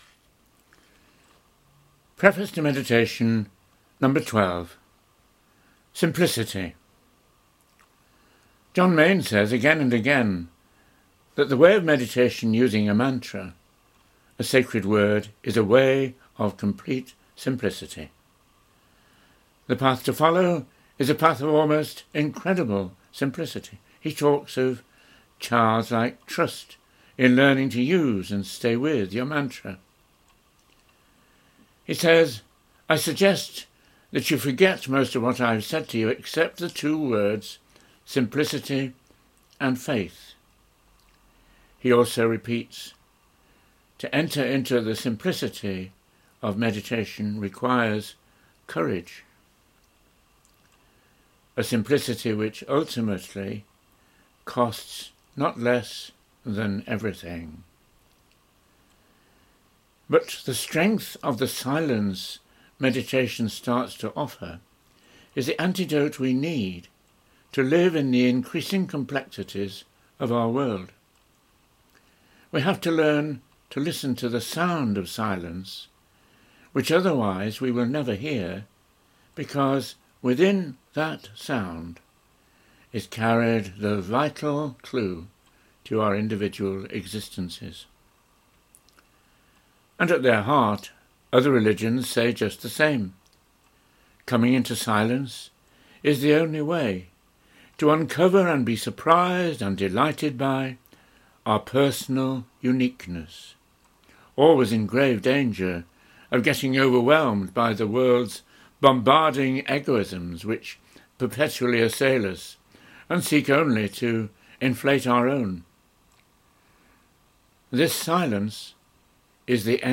Recorded Talks